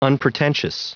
Prononciation du mot unpretentious en anglais (fichier audio)